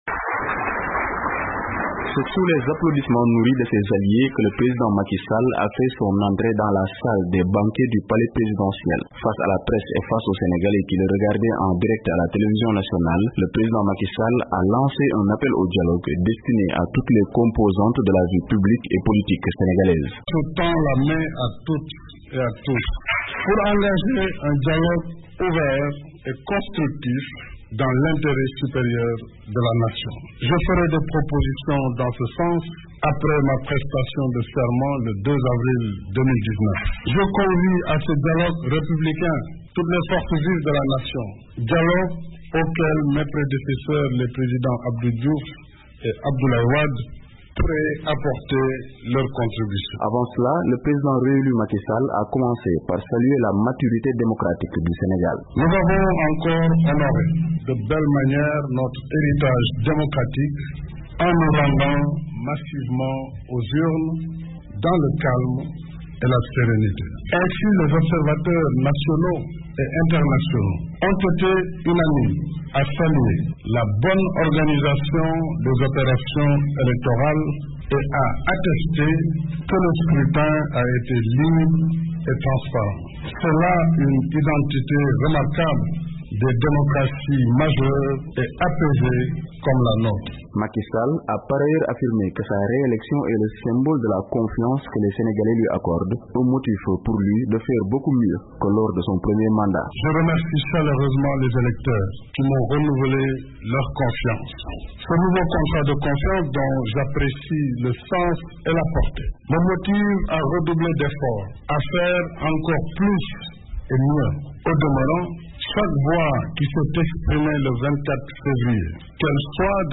Macky Sall appelle à l’unité lors de son premier discours après sa réélection
Le président Macky Sall a tenu son premier discours postélectoral, après la confirmation de sa victoire au scrutin du 24 février dernier par le conseil constitutionnel.